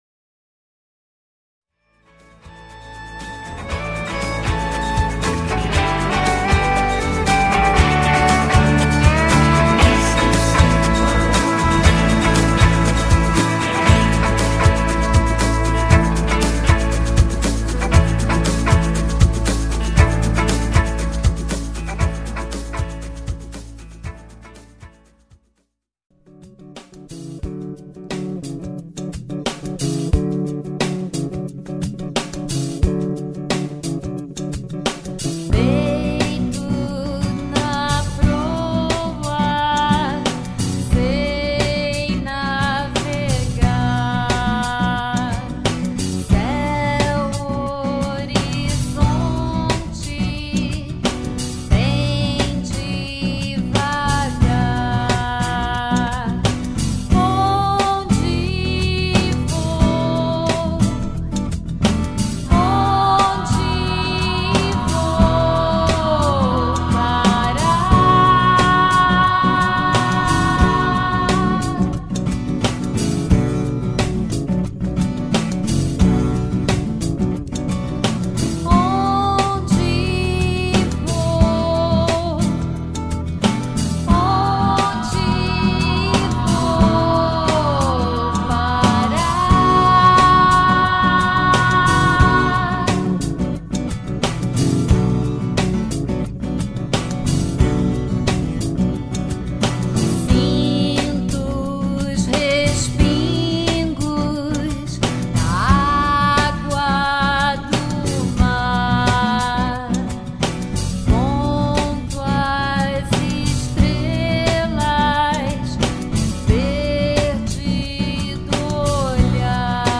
O programa vai ter entrevistas e muita música!!
As artistas são as solistas e backing vocals. Fazem múltiplas vozes e interpretam de modo todo especial as músicas criadas especialmente para elas.